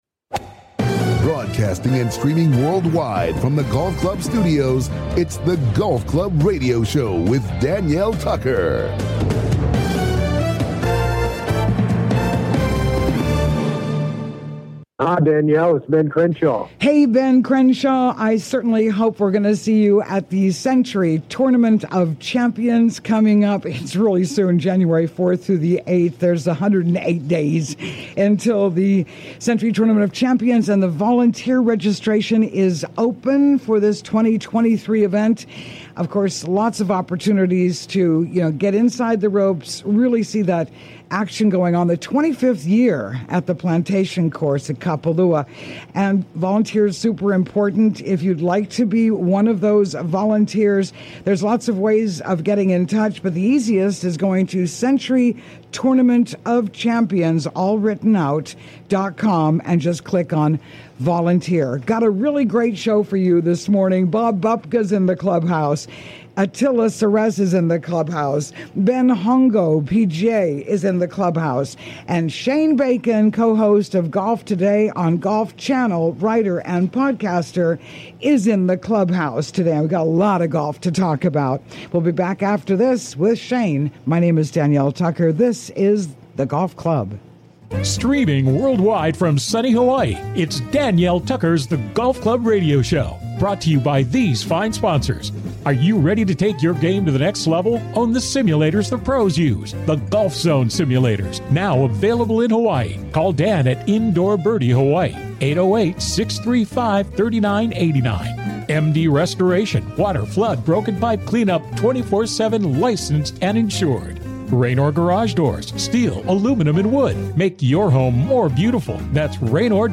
COMING TO YOU LIVE FROM THE GOLF CLUB STUDIOS ON LOVELY OAHU WELCOME INTO THE CLUBHOUSE!